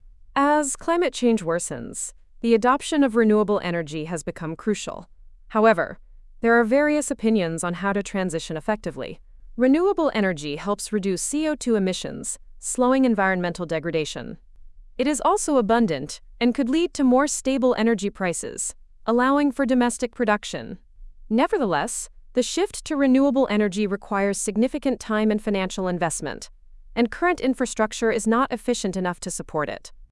要約音声はこちら：